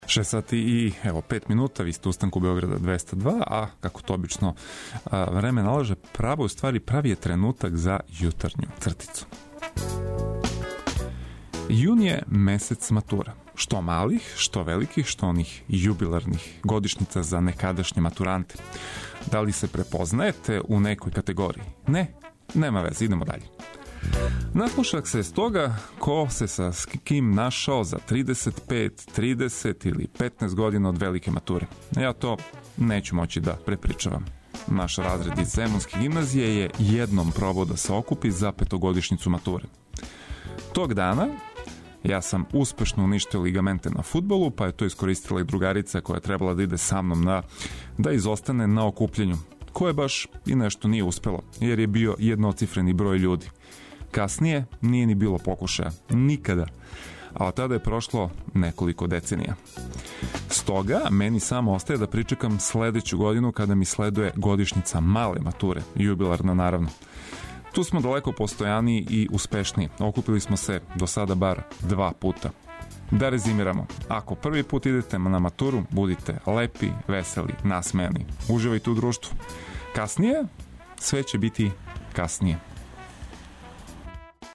Будимо се заједно уз добру музику и преглед најбитнијих информација.